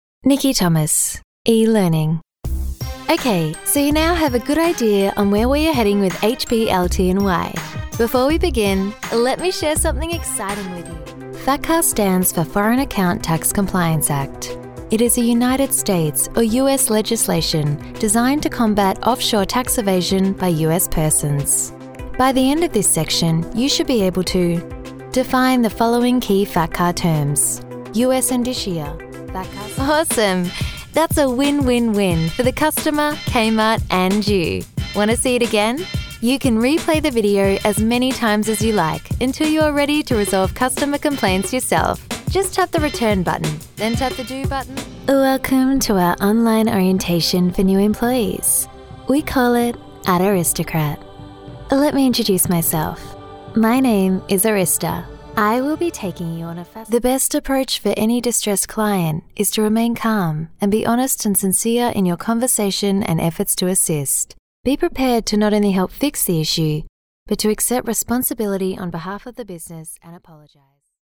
Friendly, upbeat
englisch (australisch)
Sprechprobe: eLearning (Muttersprache):
Female VO Artist